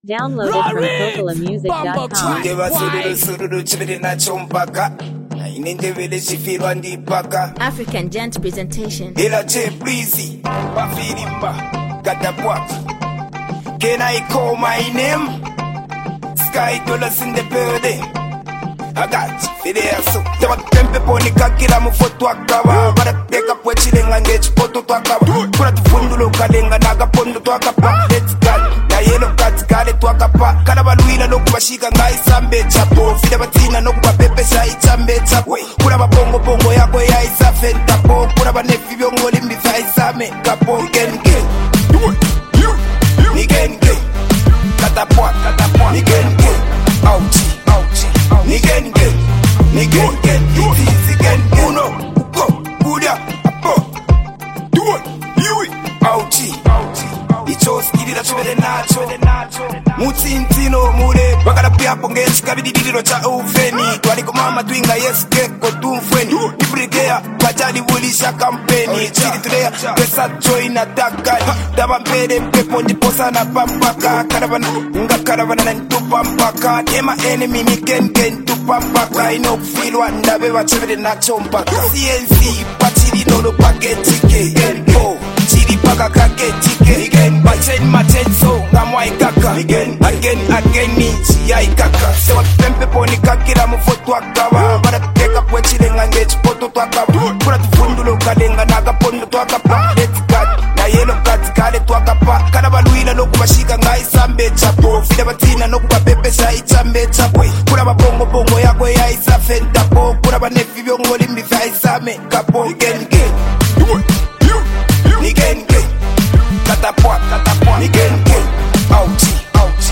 a bold, street-rooted anthem